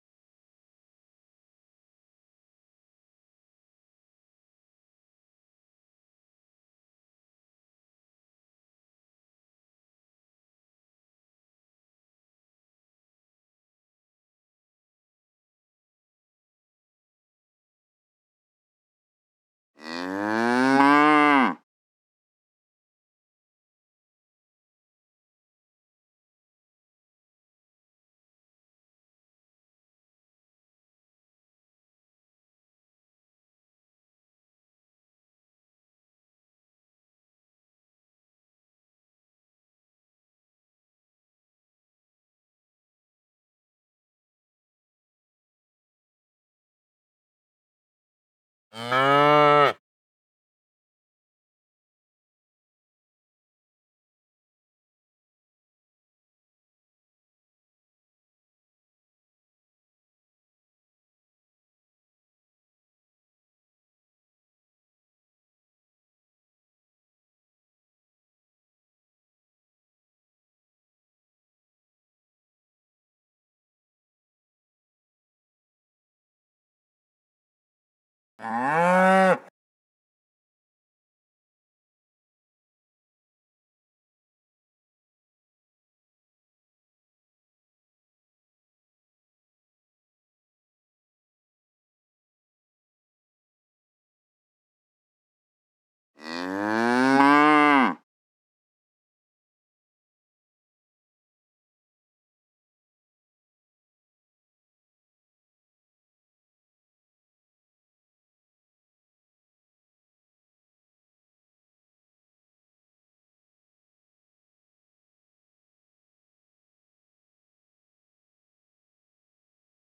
SFX_Intro_Cow_Walk.ogg